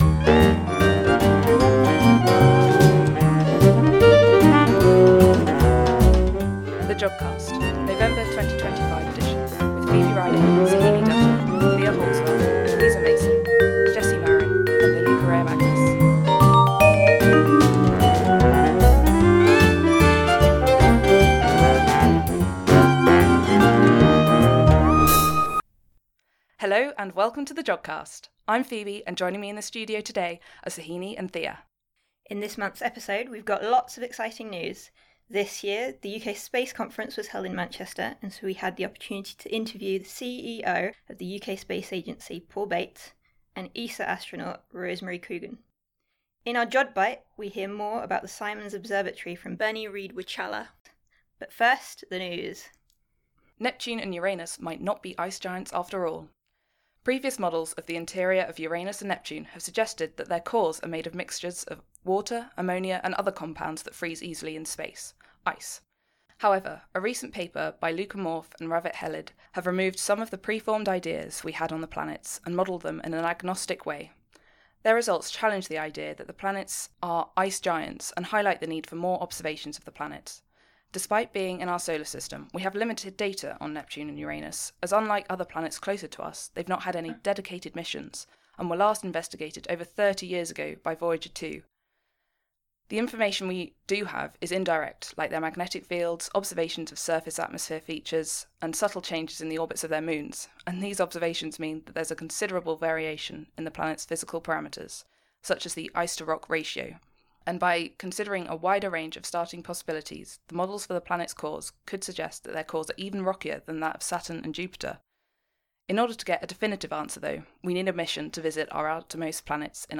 Reaching the Stars. In this month’s episode, we’ve got lots of exciting interviews. This year, the UK Space Conference was held in Manchester so we had the opportunity to interview the CEO of the UK Space Agency, Paul Bate, and ESA astronaut Rosemary Coogan.